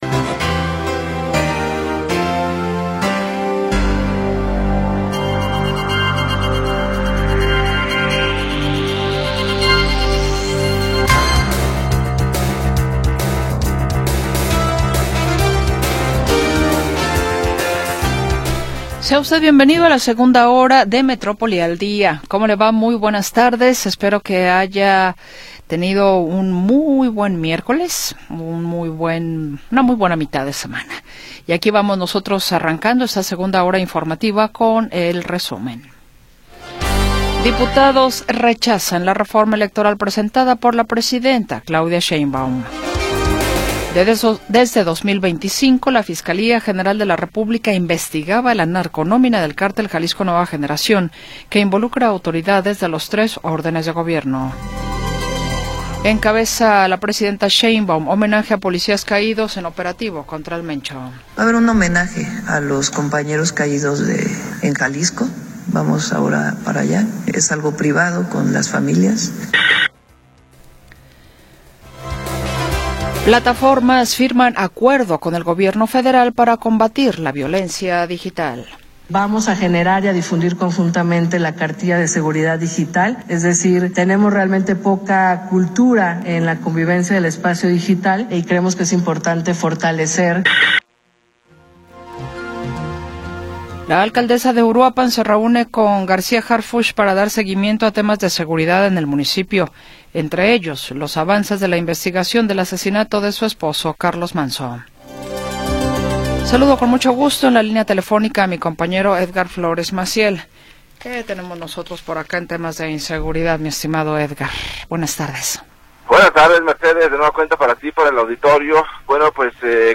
Análisis, comentarios y entrevistas